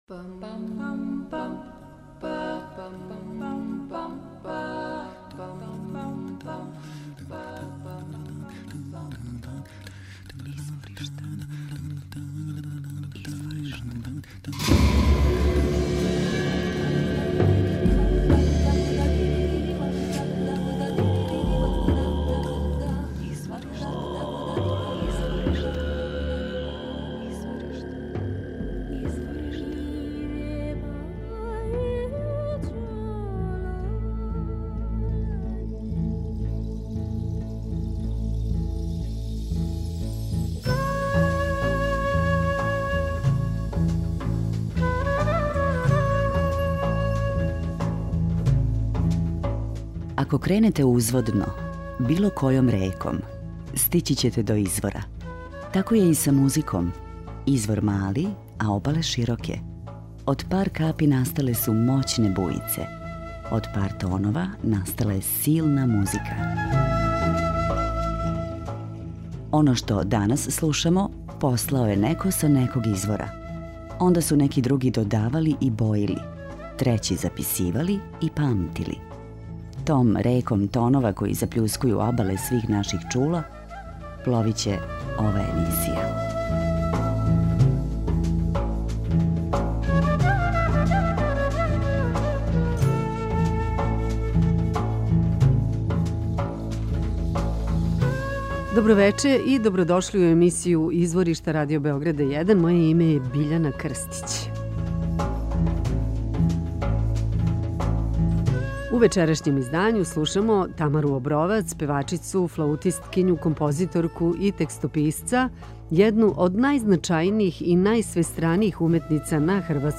Сестре Осојану, фолклорни ансамбл из Републике Молдавије.
На њиховом репертоару налазе се коледарске песме, баладе, лирске песме ...фолклор за децу(успаванке, дадиљске песме...)